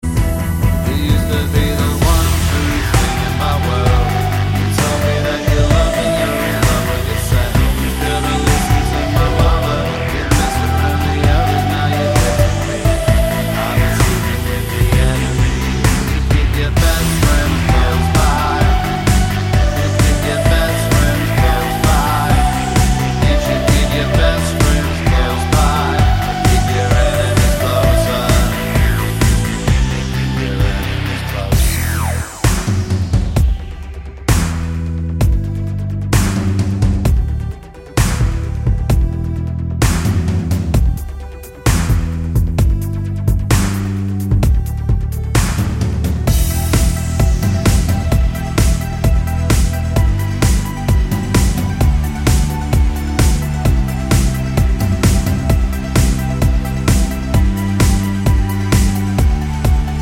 no Backing Vocals Dance 3:45 Buy £1.50